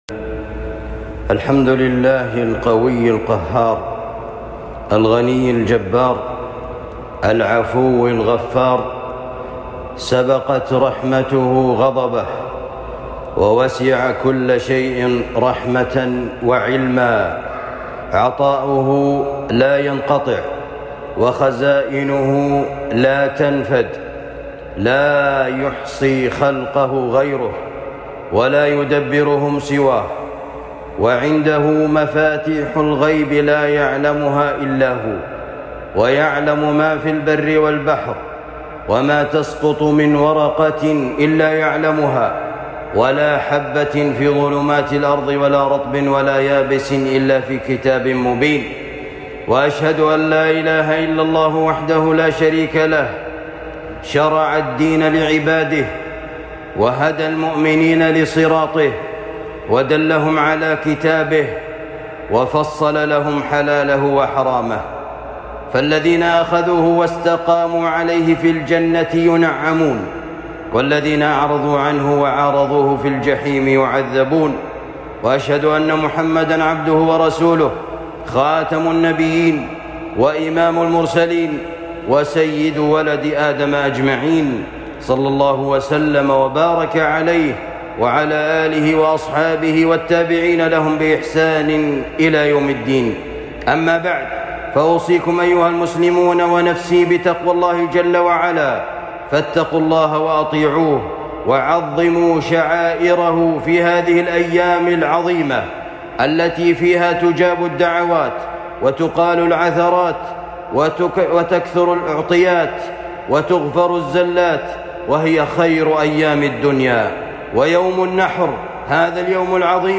خطبة عيد الأضحى المبارك ١٤٤٢